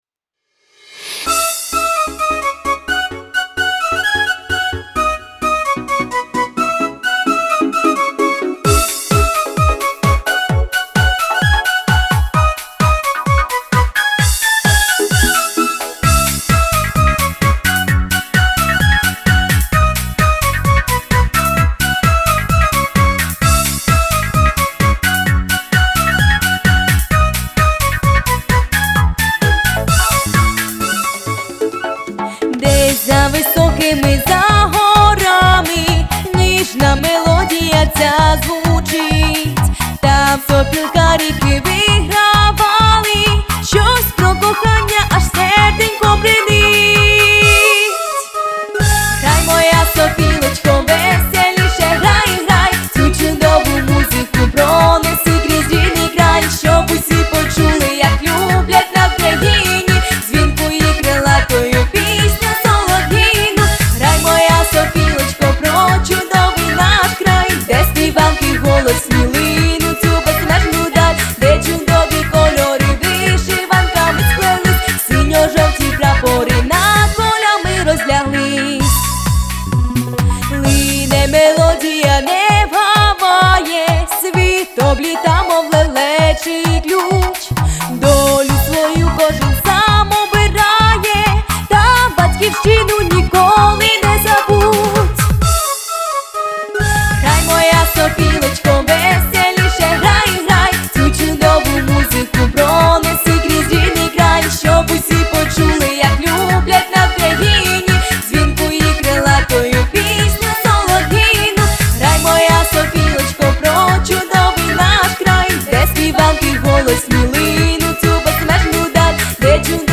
Авторська дитяча пісня середнього рівня виконання
Плюсовий запис